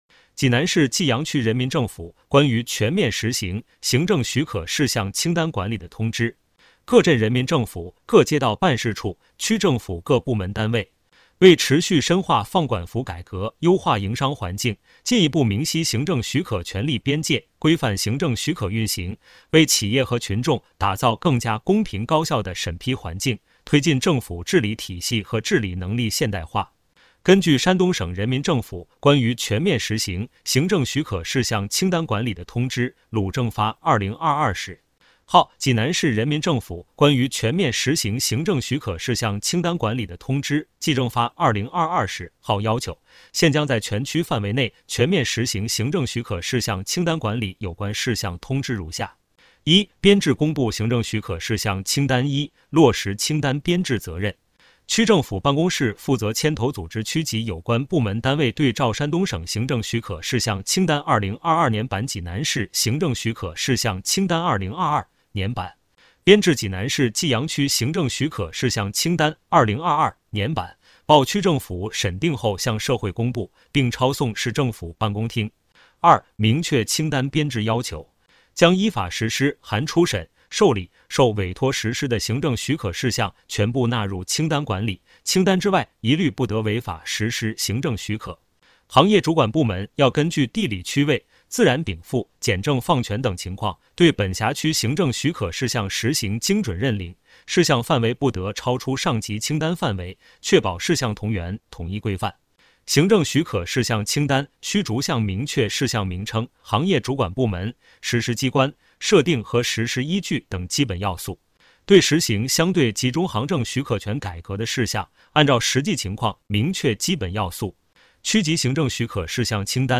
【有声朗读】济南市济阳区人民政府关于全面实行行政许可事项清单管理的通知